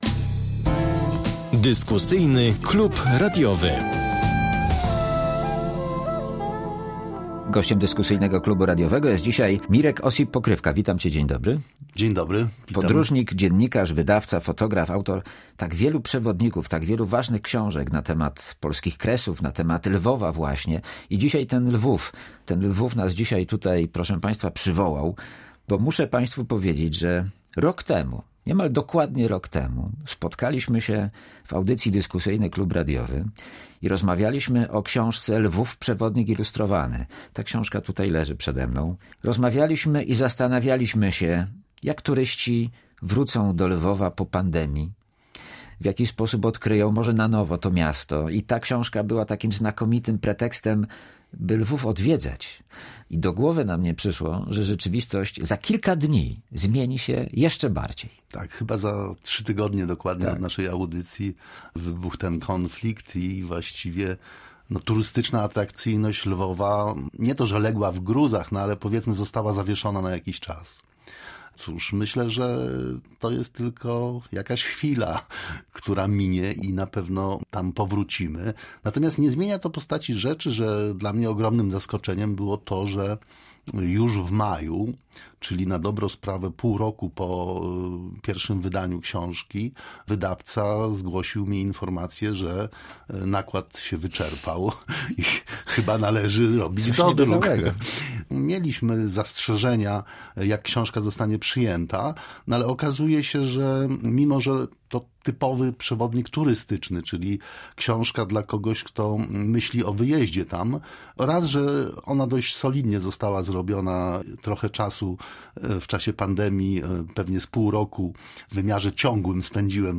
W Dyskusyjnym Klubie Radiowym rozmowa o Lwowie